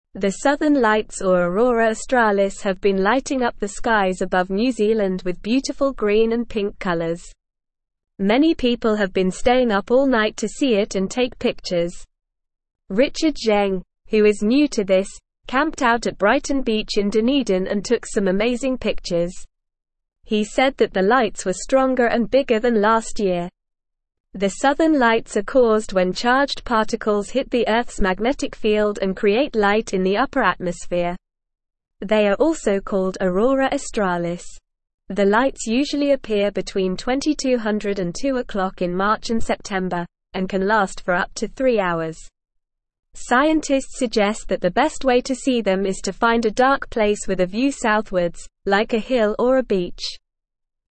Normal
English-Newsroom-Beginner-NORMAL-Reading-Pretty-Colors-in-the-Sky-at-Night.mp3